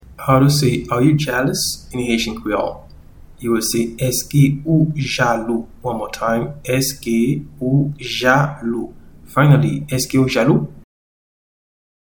Pronunciation:
Are-you-jealous-in-Haitian-Creole-Eske-ou-jalou-2.mp3